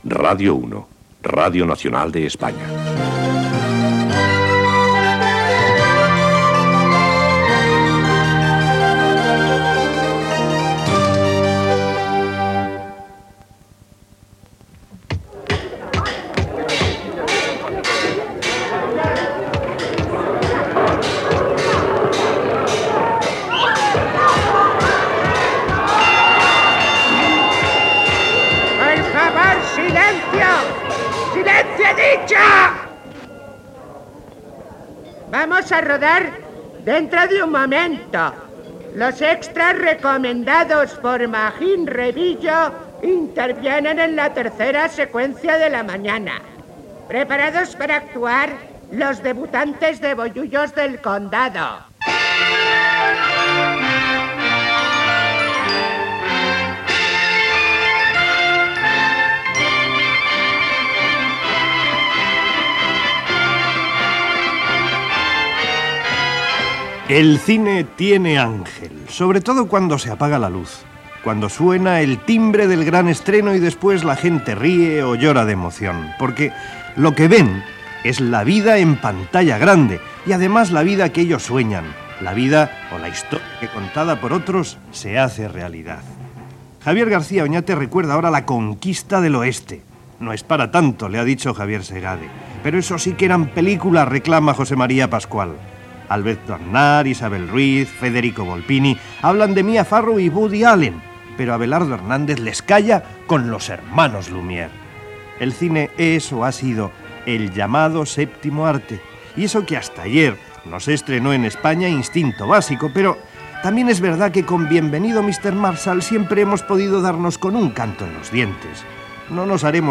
Indicatiu de l'emissora, com Radio 1 de RNE, comentari sobre el cinema i esment als noms dels col·laboradors i equip